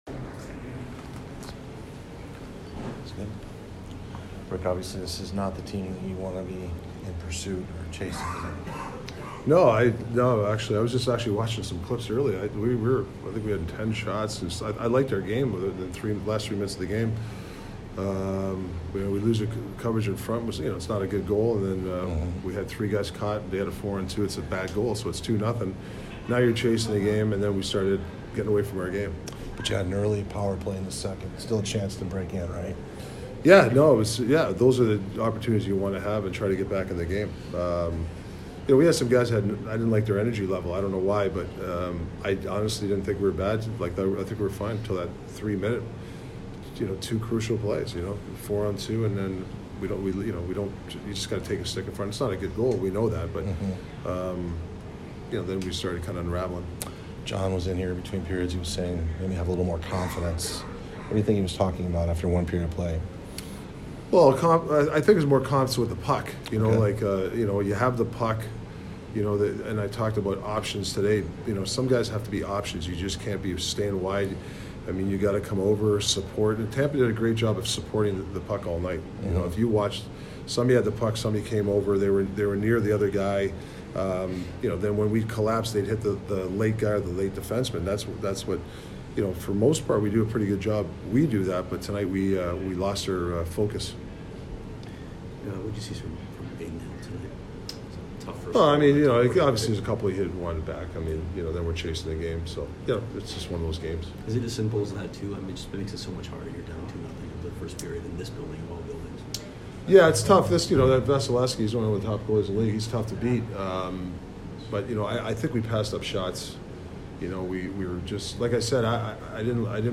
Tocchet post-game 1/9